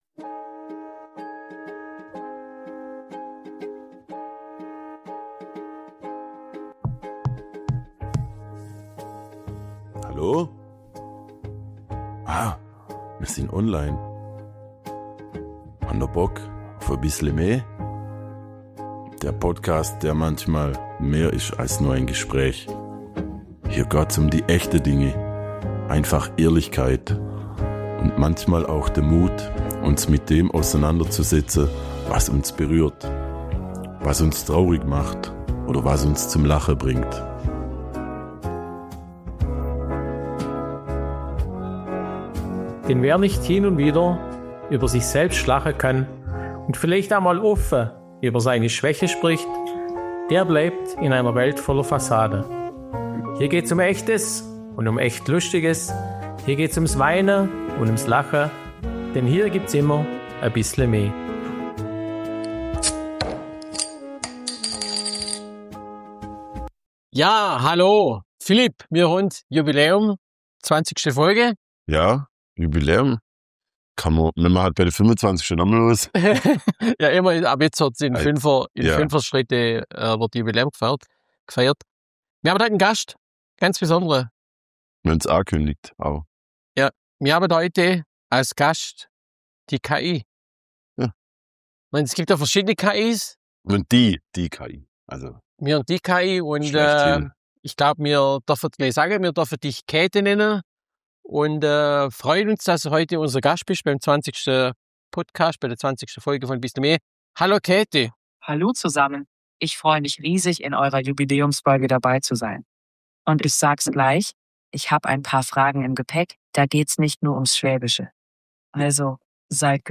Zwischen Technik, Humor und Schlafsack-Atmosphäre entsteht eine Folge, die zeigt: Zukunft kann ziemlich lustig sein.